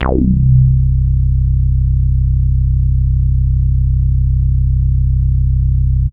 15Bass07.WAV